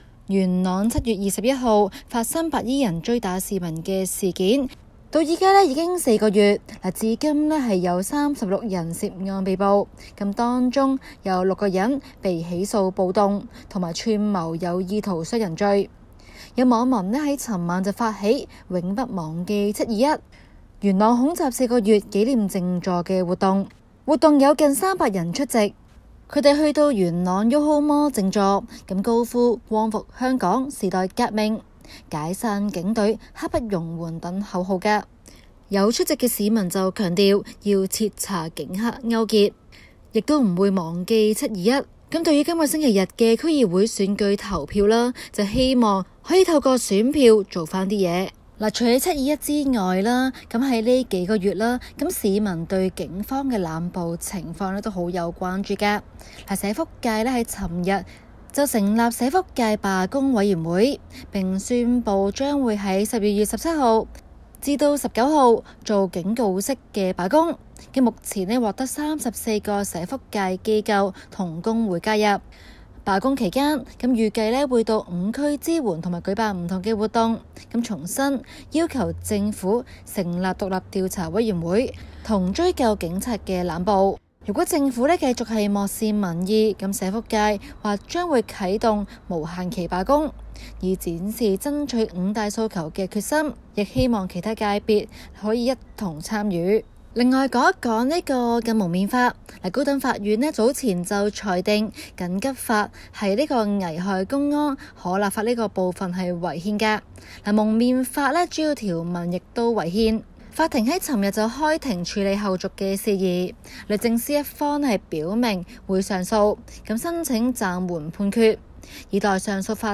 AFP Source: AFP SBS廣東話節目 View Podcast Series Follow and Subscribe Apple Podcasts YouTube Spotify Download (13.26MB) Download the SBS Audio app Available on iOS and Android 昨日香港高等法院就緊急法危害公安立法違憲部分，開庭處理後續事宜。